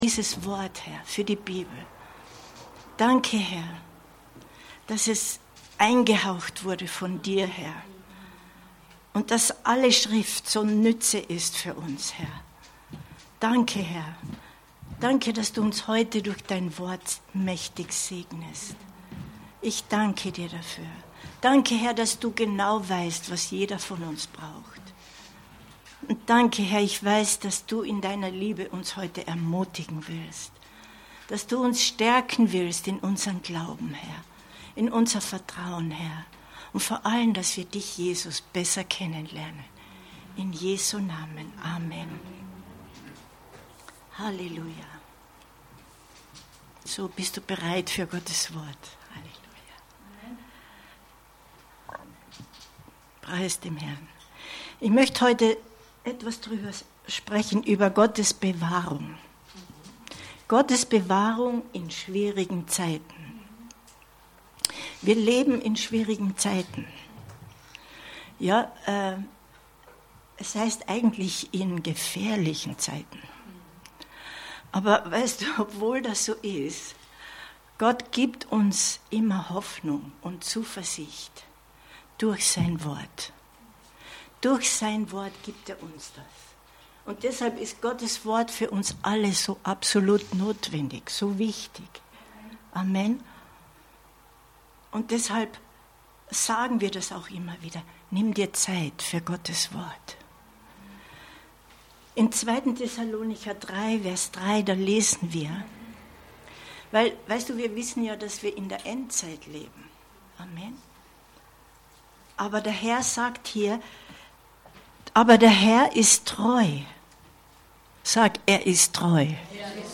Gottes Bewahrung in schwierigen Zeiten 20.08.2023 Predigt herunterladen